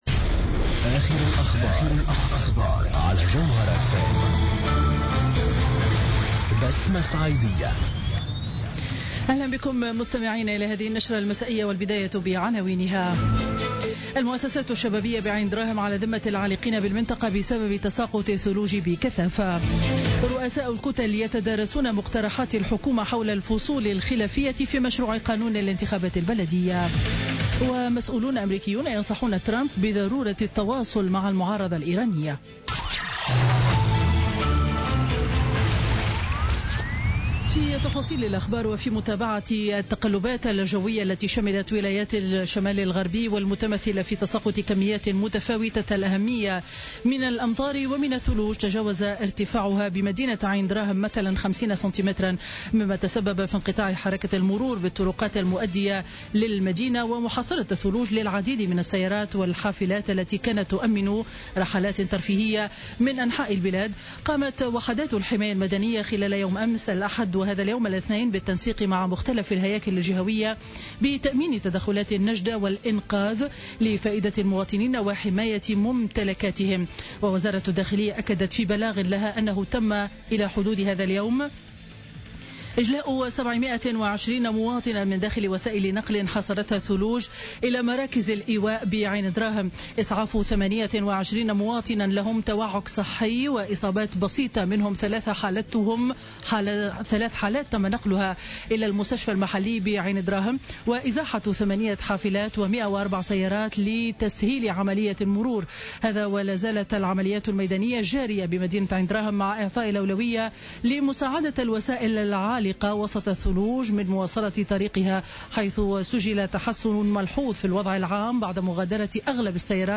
نشرة أخبار السابعة مساء ليوم الاثنين 16 جانفي 2017